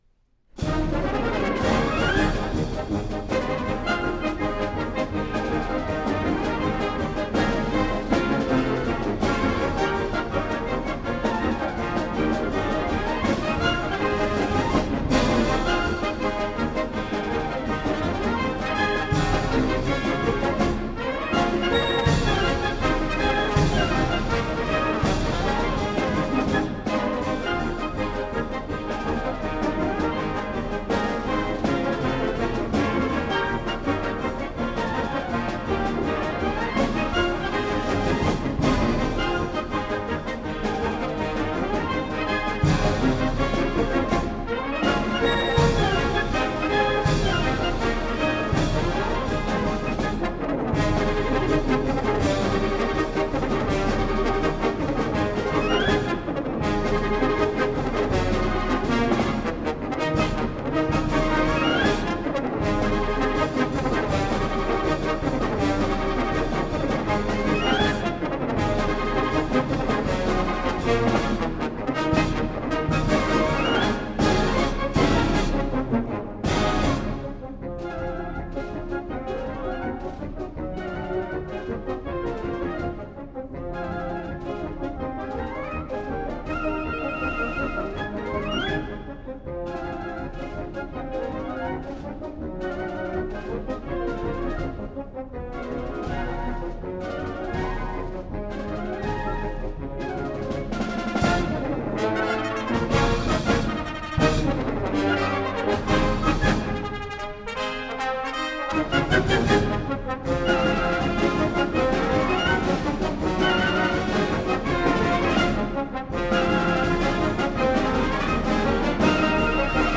Live in Concert
March, "The Circus Bee"